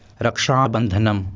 शृणु) /ˈrkʃhɑːbənðənəm/) (हिन्दी: रक्षाबन्धन, आङ्ग्ल: Raksha Bandhan) श्रावणमासस्य शुक्लपूर्णिमायाम् आचर्यते ।